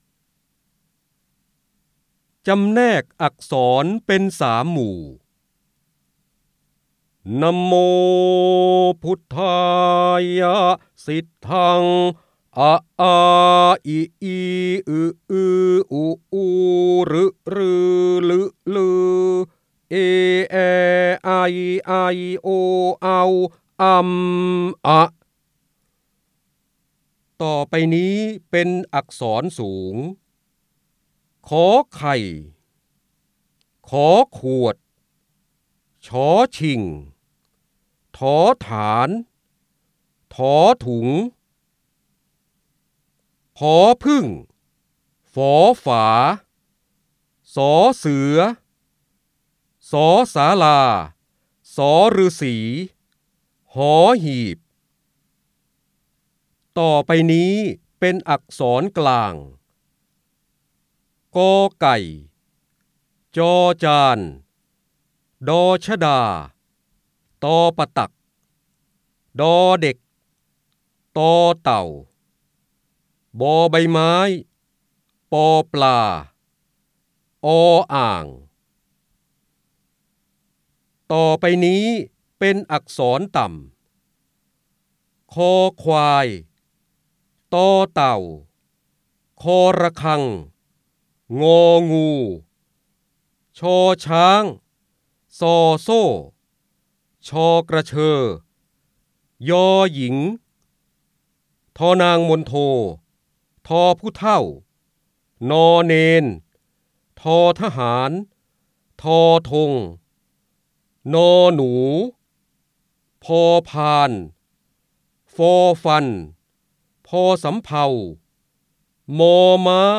เสียงบรรยายจากหนังสือ จินดามณี (พระโหราธิบดี) จำแนกอักษรเป็น3หมู่
คำสำคัญ : การอ่านออกเสียง, พระเจ้าบรมโกศ, พระโหราธิบดี, จินดามณี, ร้อยแก้ว, ร้อยกรอง